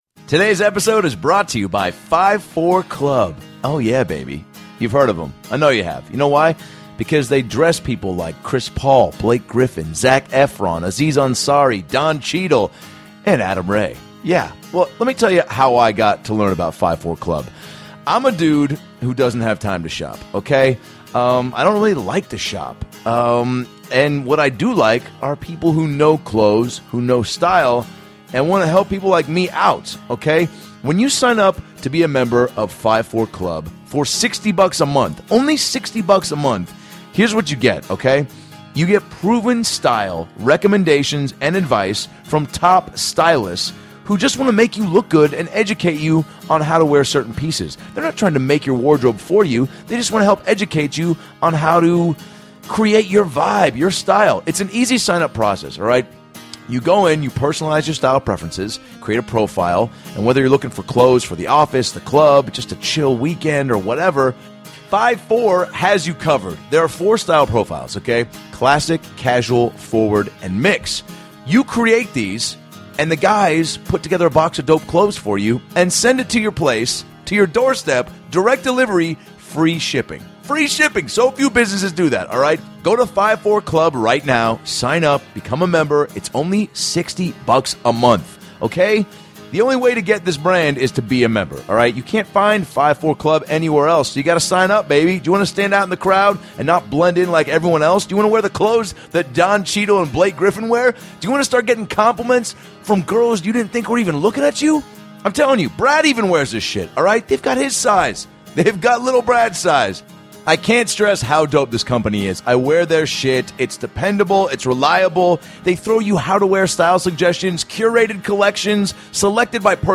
ALN - LIVE feat. Harland Williams and Iliza Shlesinger